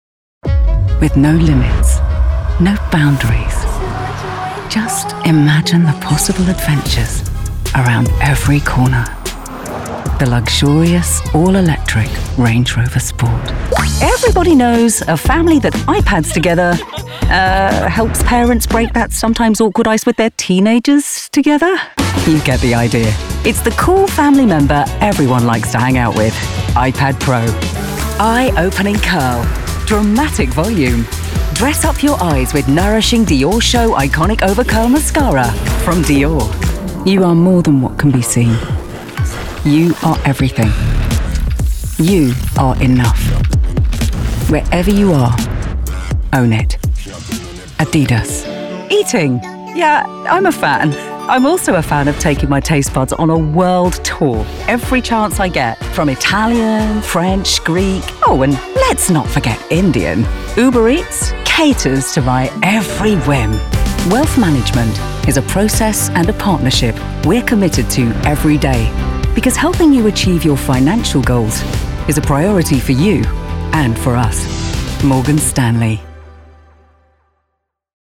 Englisch (Britisch)
Tief, Natürlich, Erwachsene, Freundlich, Warm
Kommerziell